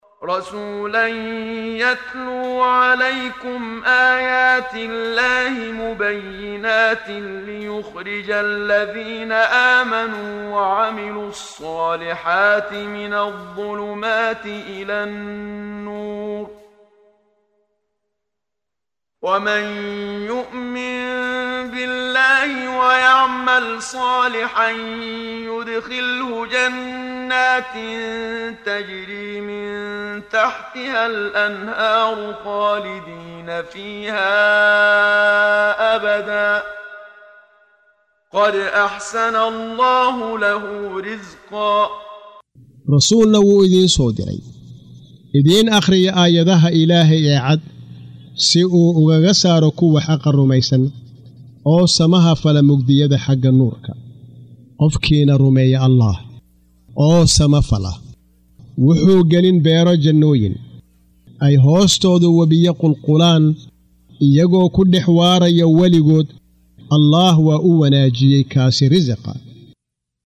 Waa Akhrin Codeed Af Soomaali ah ee Macaanida Suuradda At-Talaaq ( Furitaanka ) oo u kala Qaybsan Aayado ahaan ayna la Socoto Akhrinta Qaariga Sheekh Muxammad Siddiiq Al-Manshaawi.